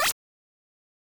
cursor.wav